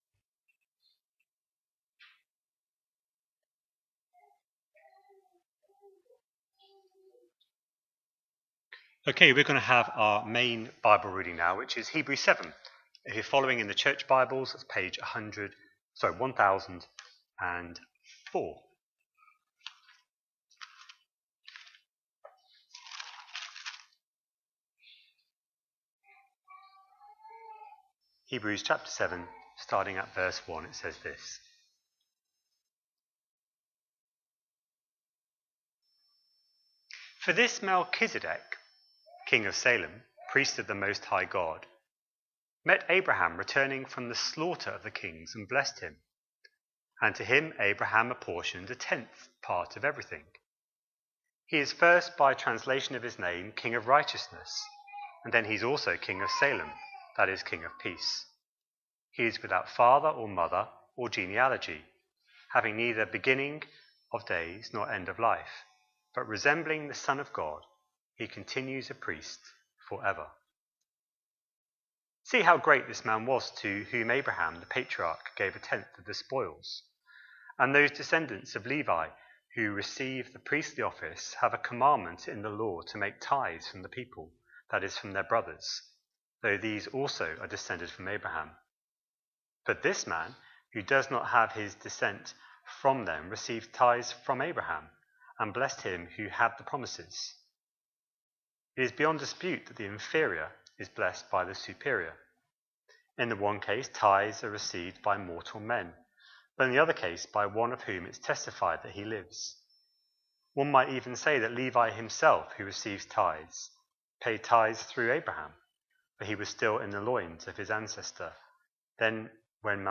A sermon preached on 12th January, 2025, as part of our Hebrews 24/25 series.